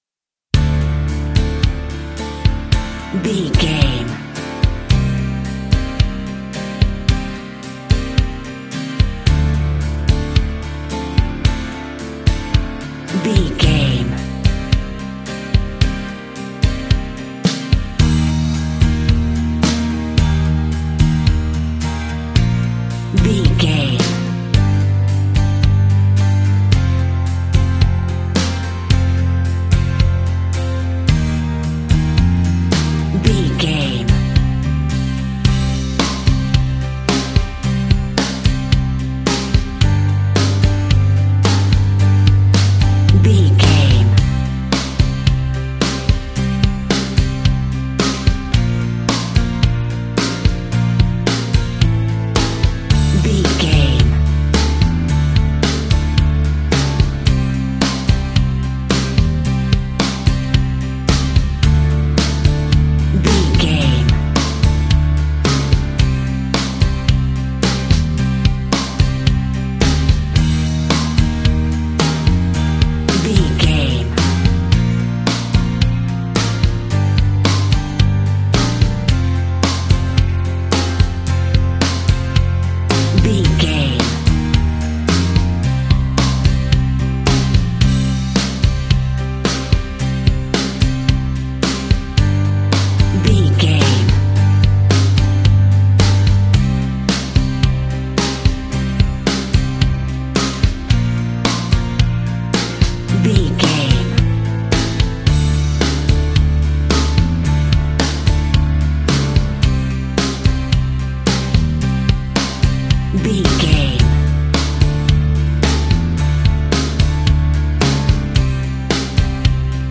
Aeolian/Minor
fun
energetic
uplifting
cheesy
instrumentals
guitars
bass
drums
organ